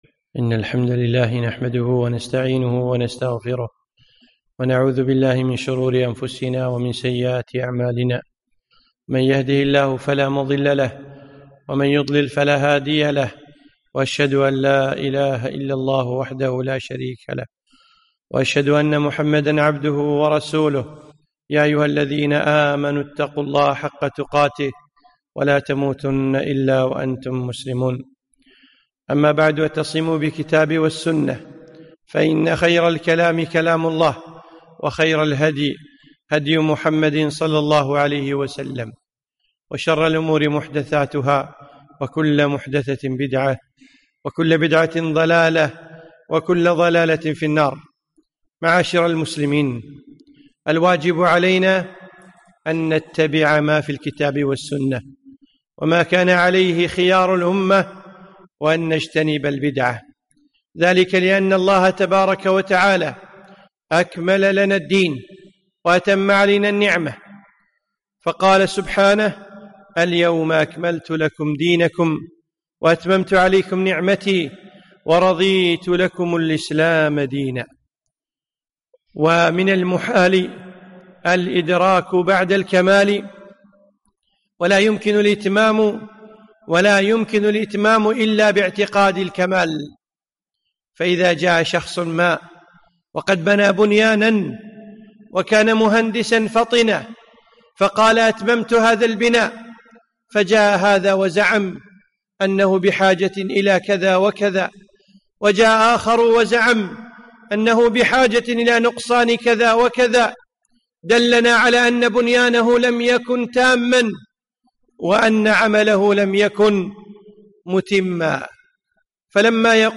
خطبة - اتبعوا ولا تبتدعوا